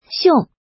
怎么读
xiòng
xiong4.mp3